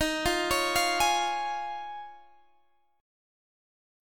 D#7sus2sus4 chord